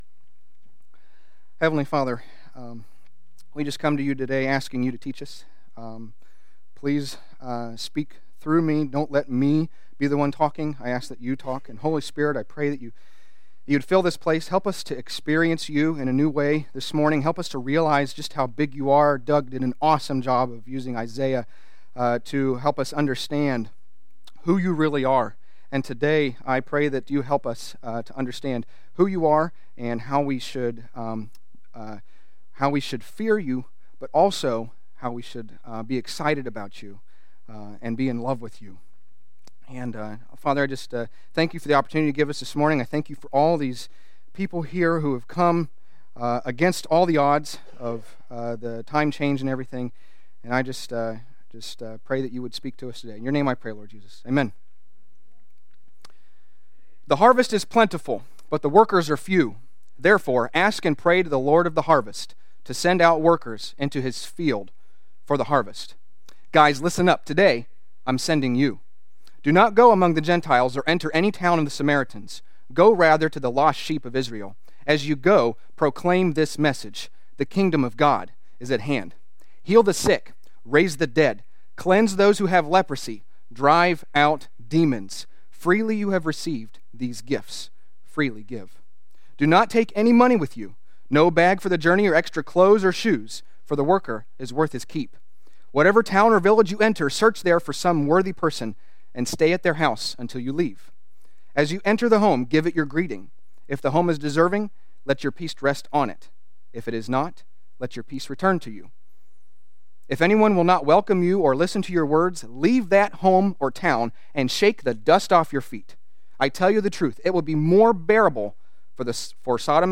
A message from the series "The 10 Words." For any relationship or society to function well, there must be a foundation built on trust.